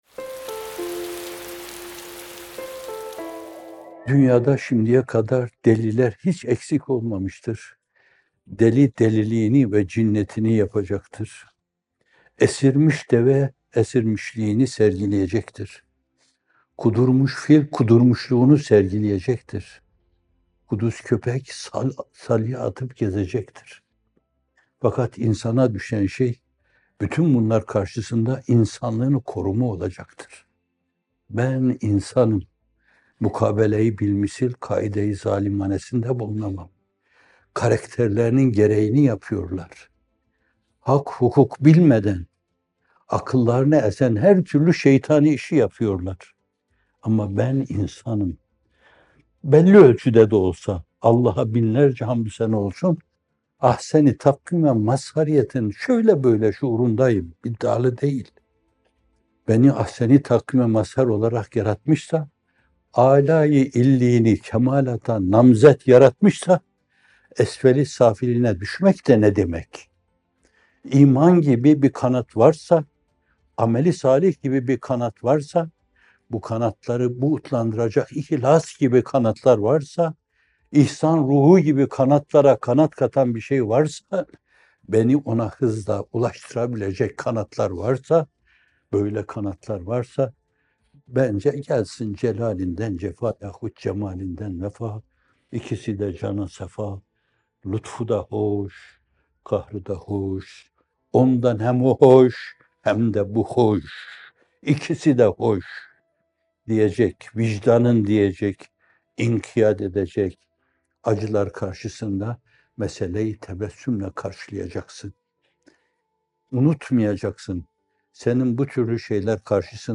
İkindi Yağmurları – Çekmeden Olur mu? - Fethullah Gülen Hocaefendi'nin Sohbetleri
Not: Bu video, 25 Mart 2019 tarihinde yayımlanan “Hüzün, Gönül ve Dil” isimli Bamteli sohbetinden hazırlanmıştır.